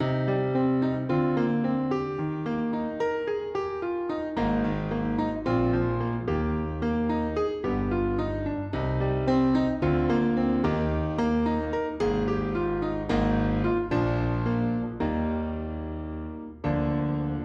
RNB钢琴110bpm Dmajor
Tag: 110 bpm RnB Loops Piano Loops 2.94 MB wav Key : D